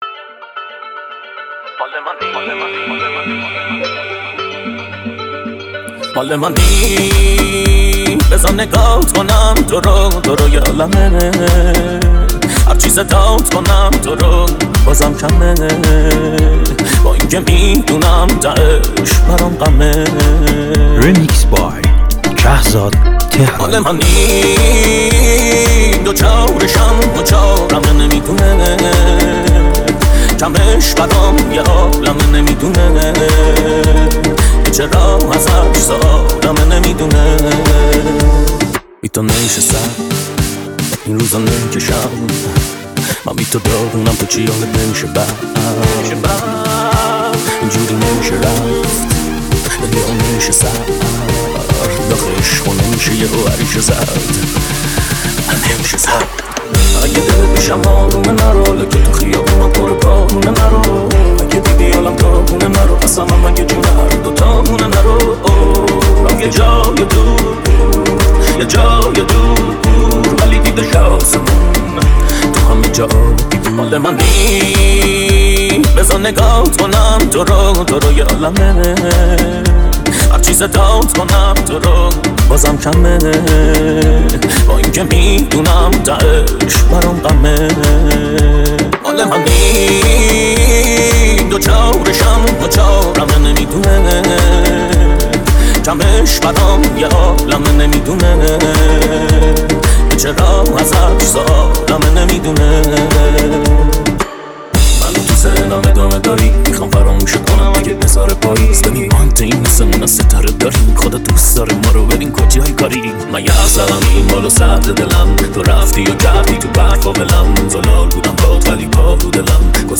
تند بیس دار